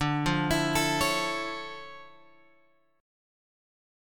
D Minor Major 9th